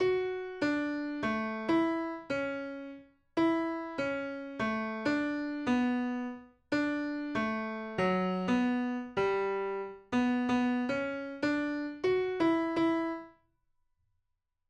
Ich komme jetzt nicht auf den Namen, darum habe ich die Melodie mal kurz eingespielt, vielleicht kommt dann jemand darauf.